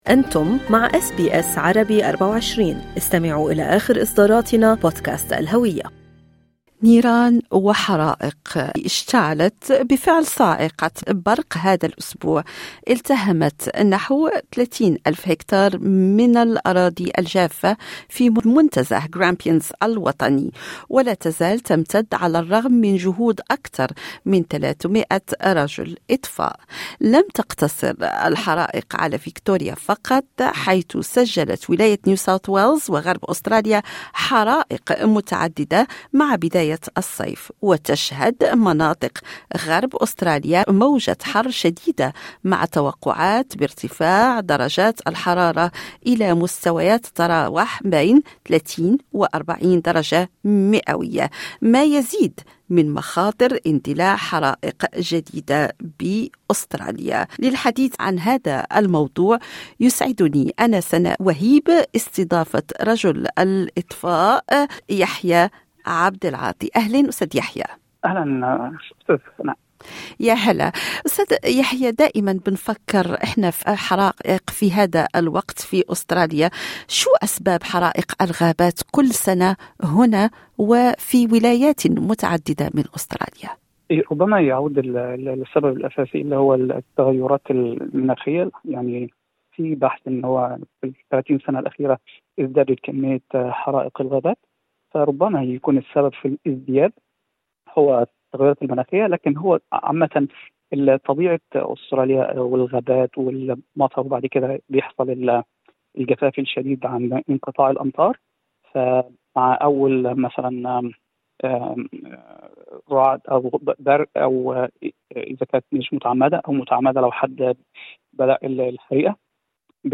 لما تندلع الحرائق في الغابات الأسترالية كل سنة؟ وكيف يتم إخمادها؟ الأجوبة مع رجل الإطفاء